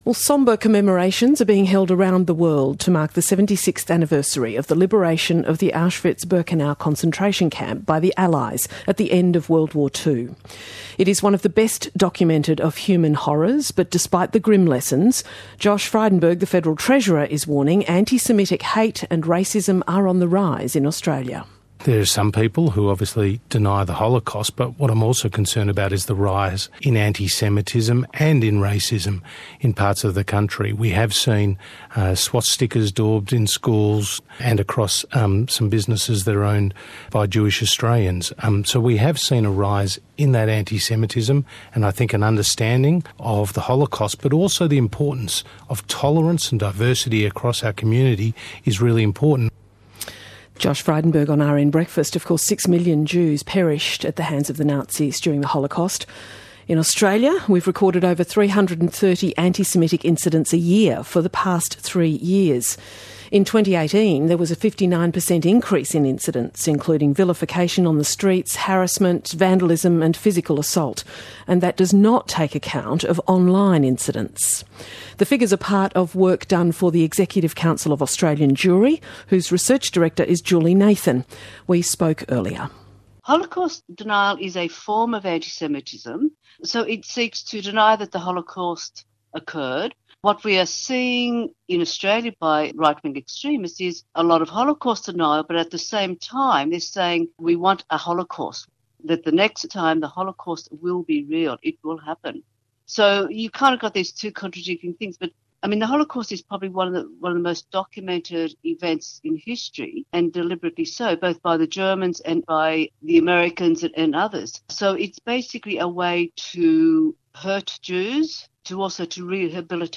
Interview: ECAJ discusses the Holocaust and right-wing extremism on ABC Radio - ECAJ